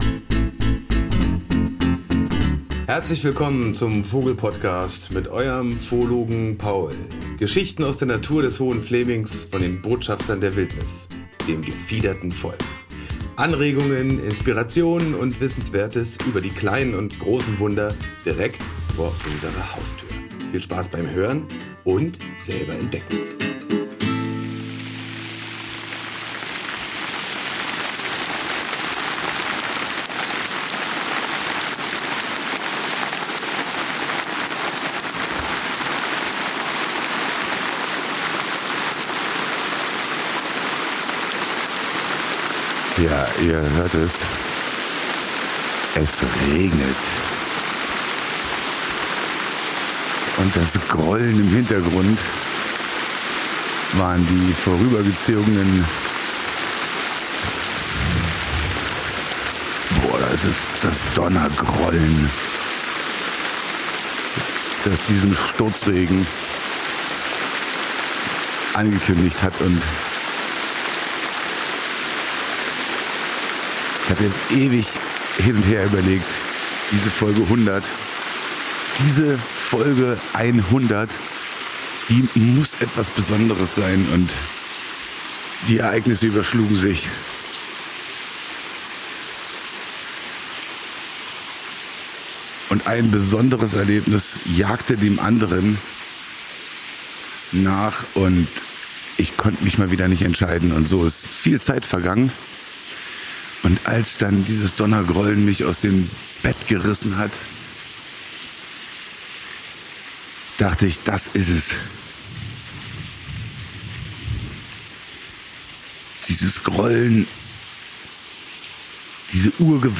Die Jubiläumsfolge sollte etwas Besonderes sein, daher fällt sie aus der chronologischen Reinfolge der vorherigen und nachfolgenden Podcasts. Ich sitze inmitten eines heftigen Sommergewitters, lasse meine Gedanken freien Lauf und lade Euch ein, mit mir den Urgewalten zu lauschen.